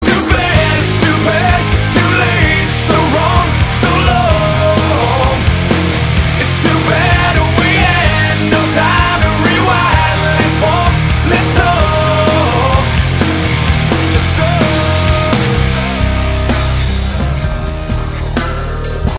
Громкая! Качество на 5ку!